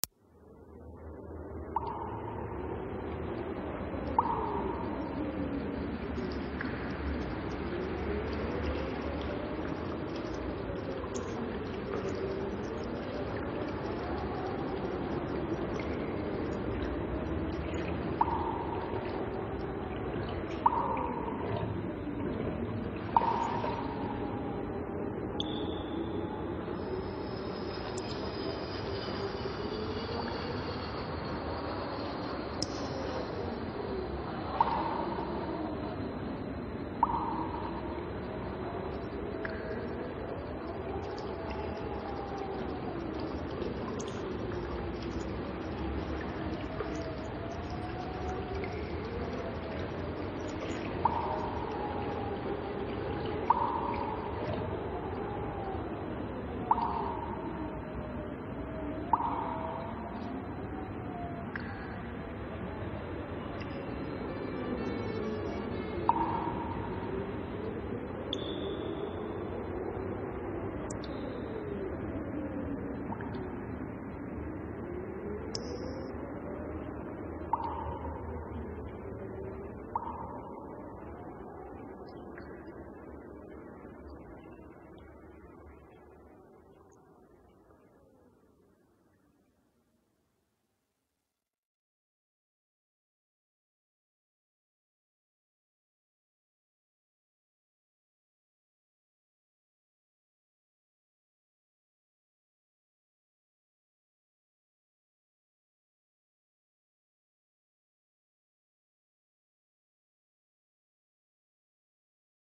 efectos-de-sonido-terror-ruidos-siniestros-en-un-sotano-mientas-se-escucha-la-calle.mp3
KGot4I6yHCt_efectos-de-sonido-terror-ruidos-siniestros-en-un-sotano-mientas-se-escucha-la-calle.mp3